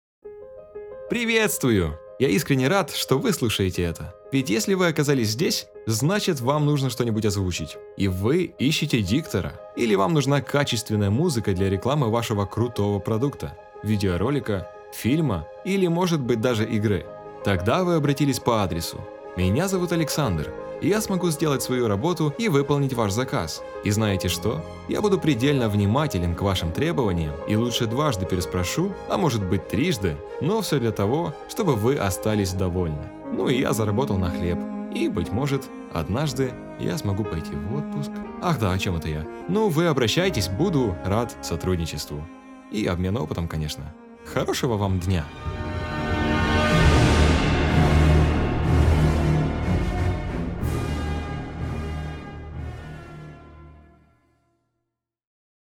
Запись моего приветствия и демо по совместительству. Музыка в записи также моя.